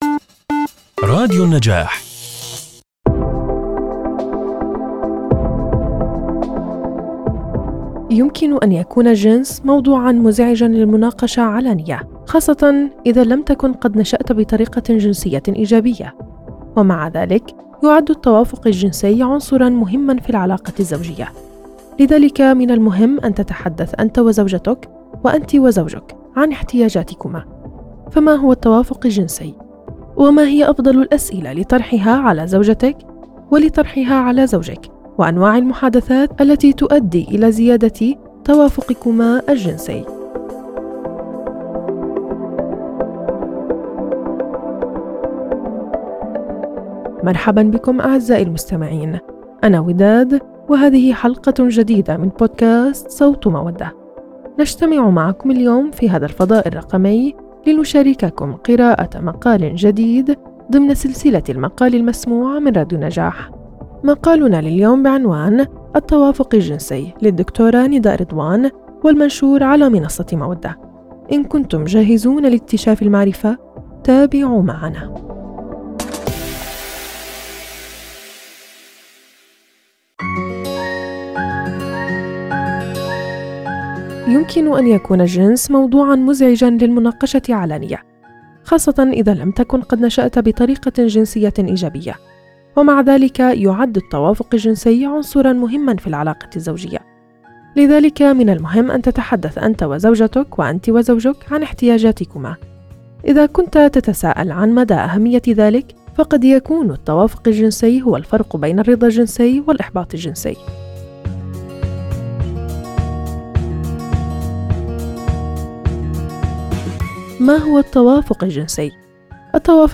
في هذا البودكاست، ننقل لكم المقالات المتنوعة والغنية المنشورة على منصة مودة إلى عالم الصوت، مما يوفر تجربة استماع ممتعة وملهمة.